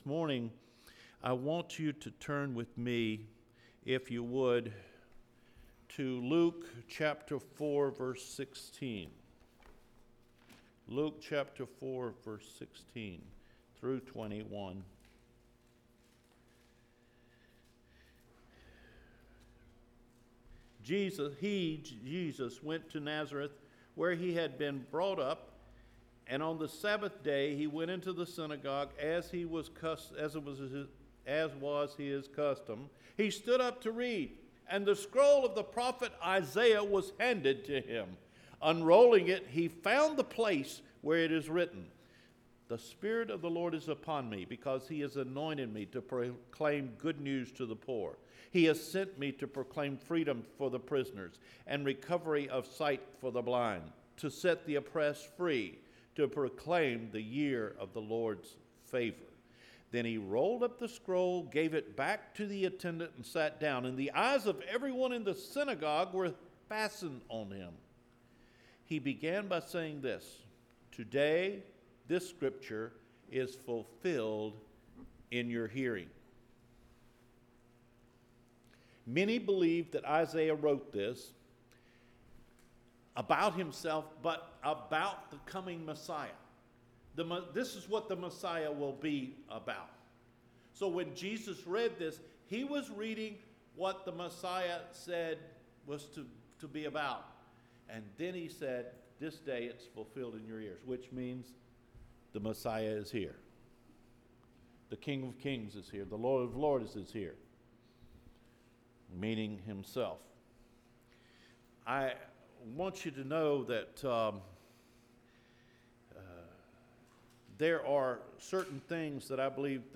COME FOLLOW ME AND I WILL MAKE YOU? – OCTOBER 6 SERMON